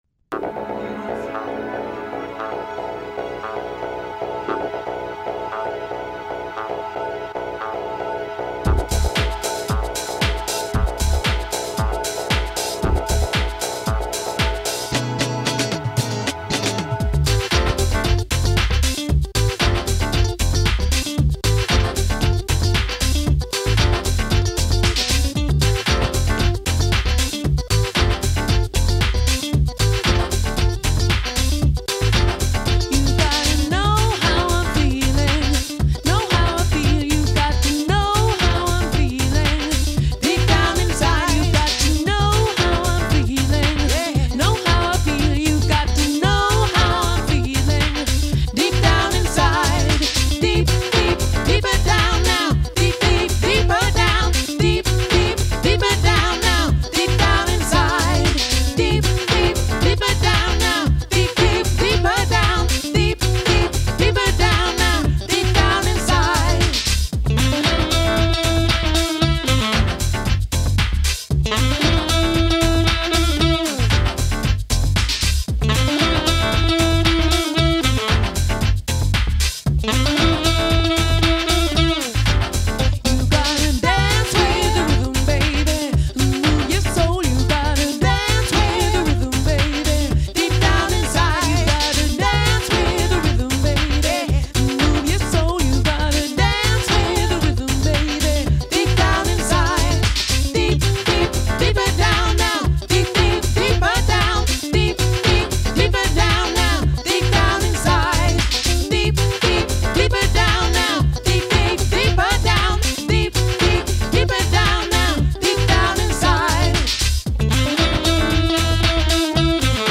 Here are some demo tapes that survived from back in the days...